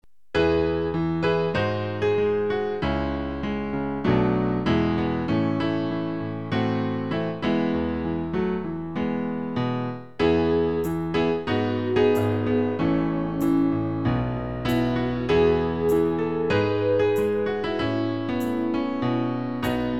Klavier-Playback zur Begleitung der Gemeinde MP3 Download